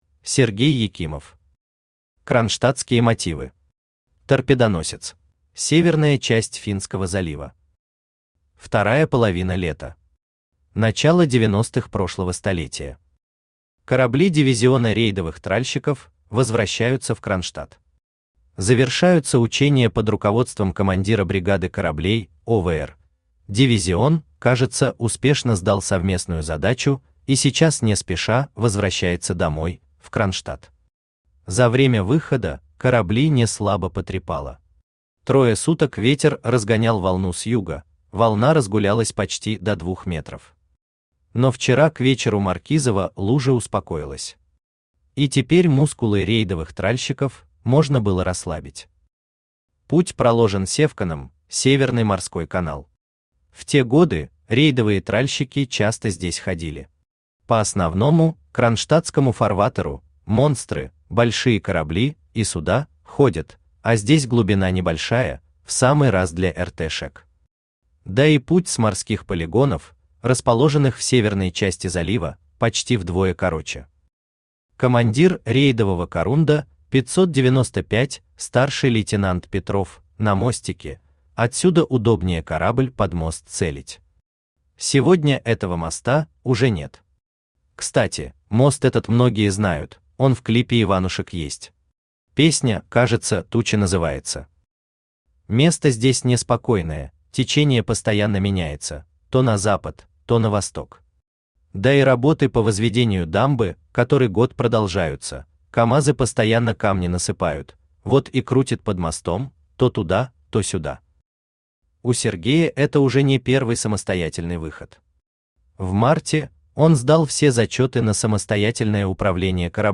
Аудиокнига Кронштадтские мотивы. Торпедоносец | Библиотека аудиокниг
Торпедоносец Автор Сергей Петрович Екимов Читает аудиокнигу Авточтец ЛитРес.